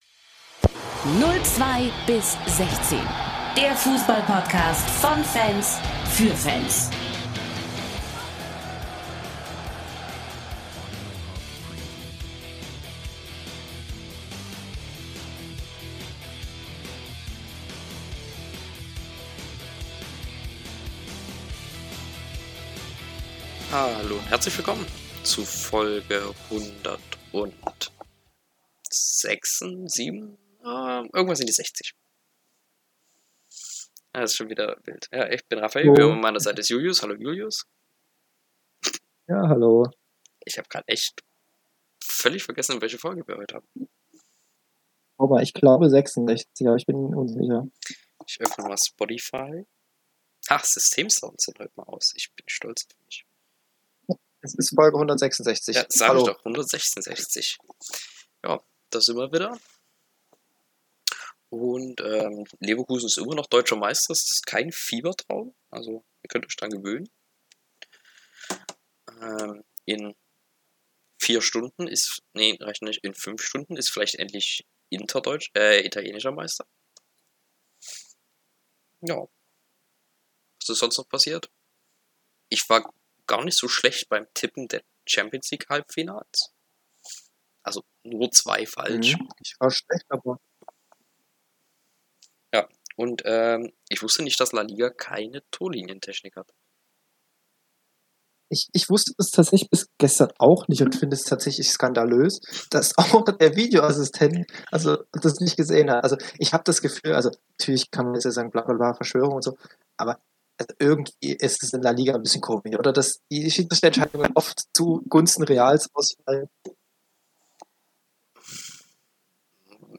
Einhundertsechsundsechzigste Folge des Herzensprojekts zweier bester Freunde.